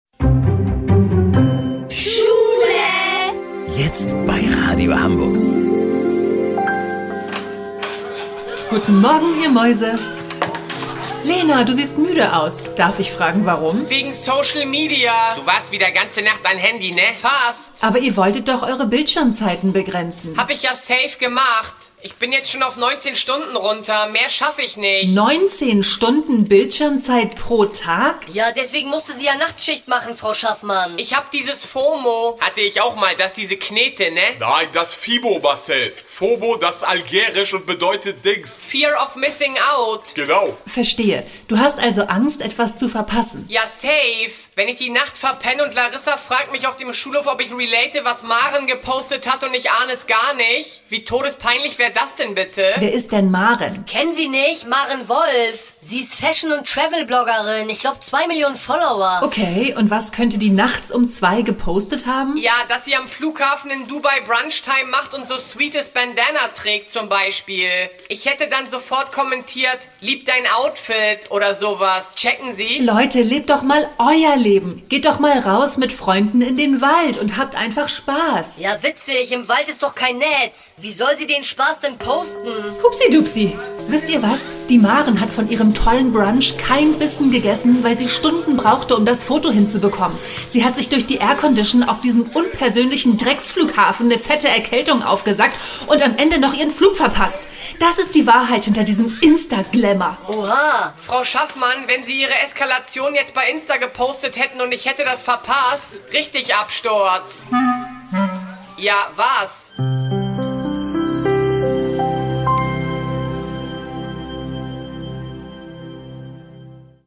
Comedy